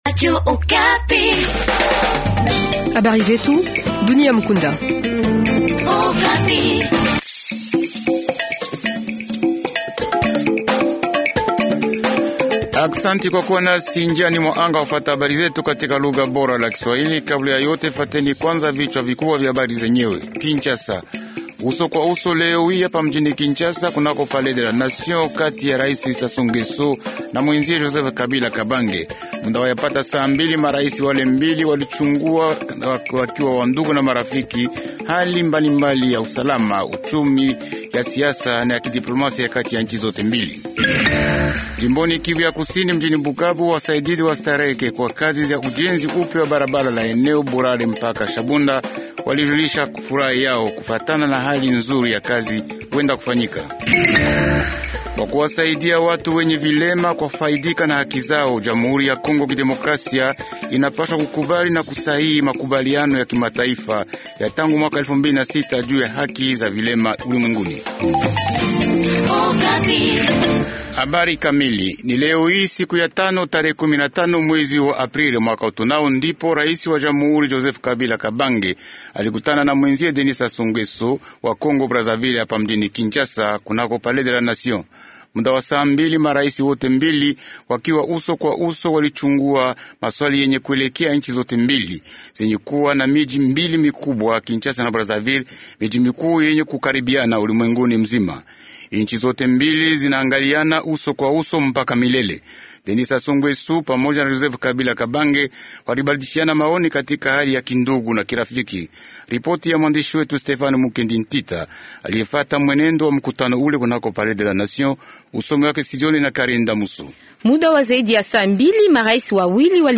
Journal Swahili Soir